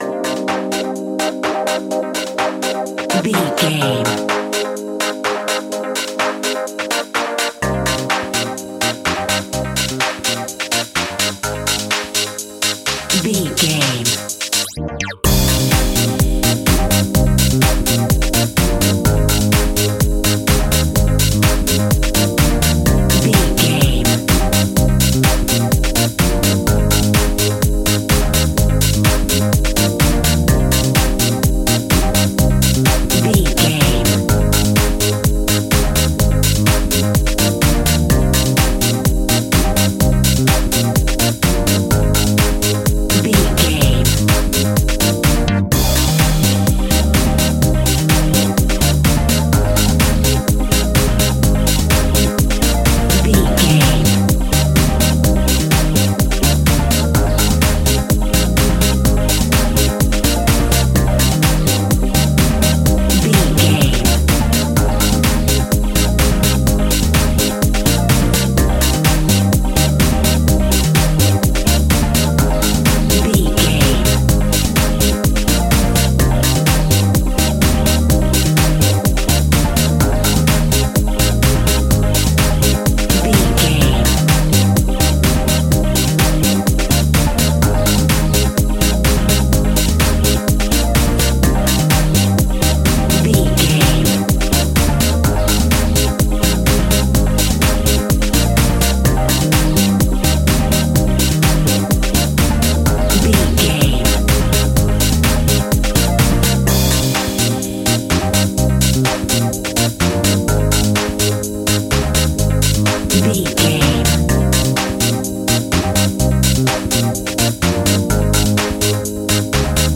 Aeolian/Minor
groovy
hypnotic
electric guitar
synthesiser
drum machine
funky house
electro funk
energetic
upbeat
synth bass
synth lead
Synth Pads
electric piano
bass guitar
clavinet
horns